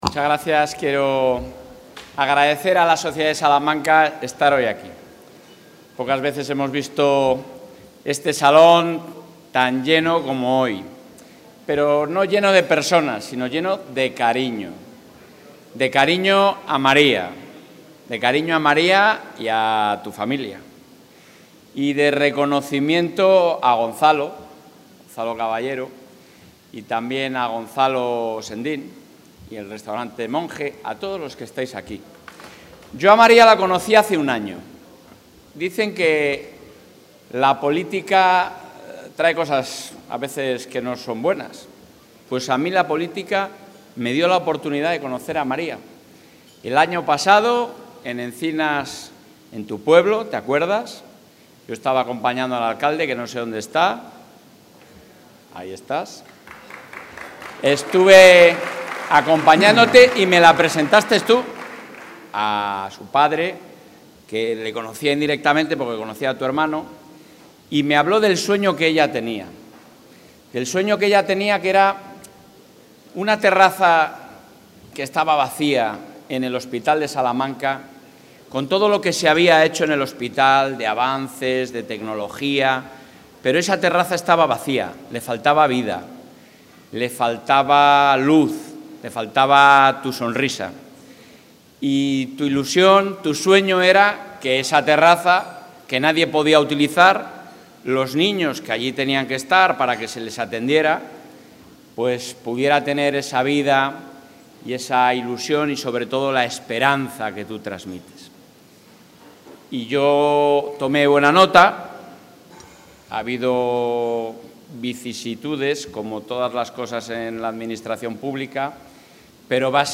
Intervención del presidente de la Junta.
El presidente de la Junta de Castilla y León, Alfonso Fernández Mañueco, ha asistido esta noche en Salamanca a la gala benéfica para la creación de la Fundación ‘La Sonrisa de María’. Durante su intervención, ha anunciado que el Hospital de la ciudad contará con 'El Jardín de María', un nuevo espacio de juegos y ocio para los niños atendidos en el área pediátrica.